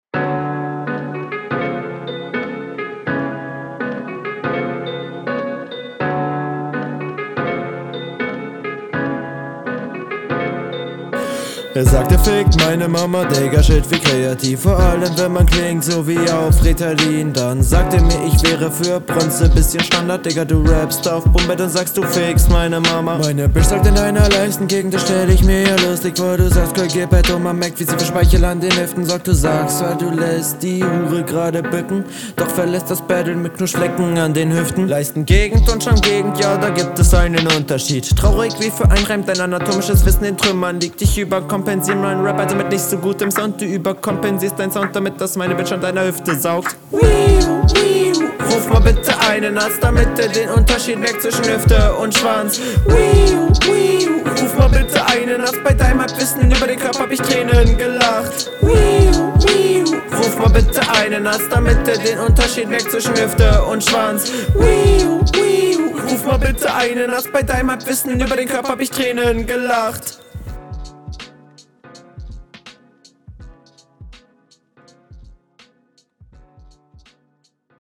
*holt luft* stimmeinsatz viel zu gedrückt für so nen entspannten beat flow auch sehr anstrengend …